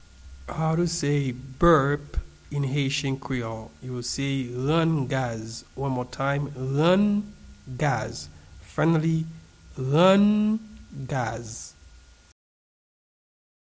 Pronunciation and Transcript:
Burp-in-Haitian-Creole-Rann-gaz-pronunciation.mp3